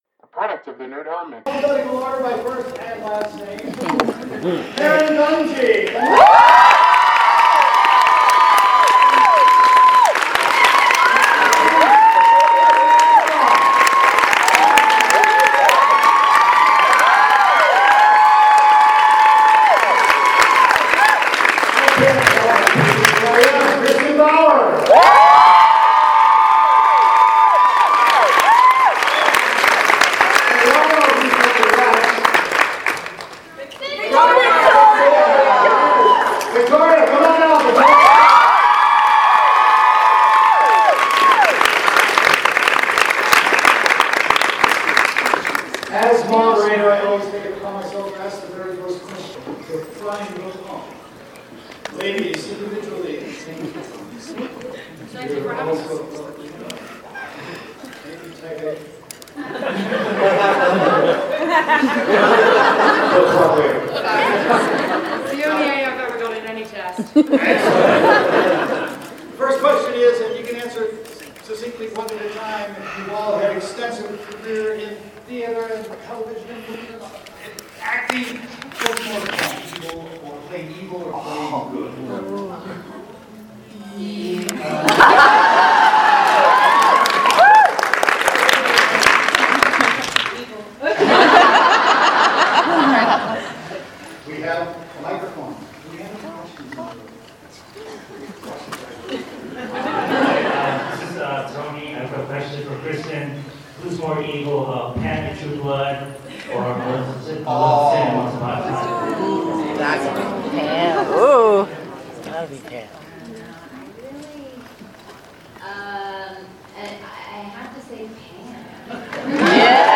Here is the audio of the panel below, apologies for some maneuvering you may hear:
Queens-of-Darkness-Panel-Awesome-Con-2015.mp3